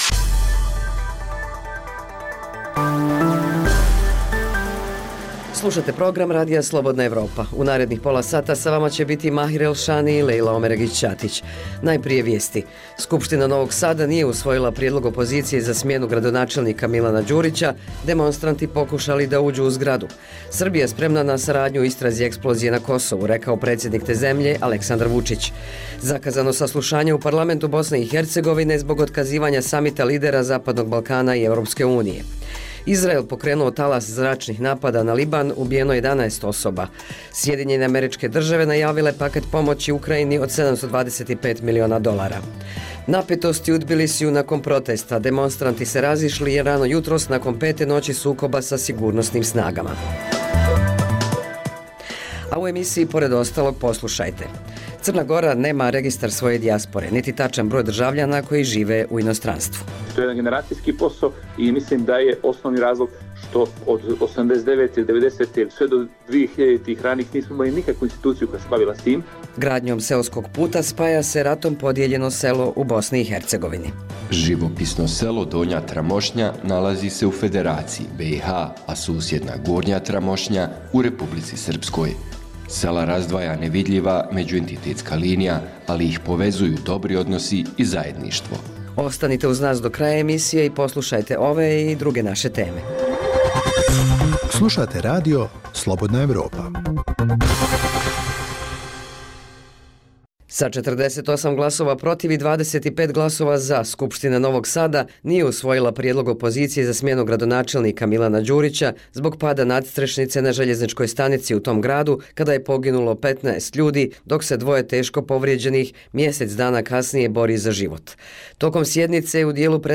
Dnevna informativna emisija sa vijestima, temama, analizama i intervjuima o događajima u Bosni i Hercegovini, regionu i svijetu.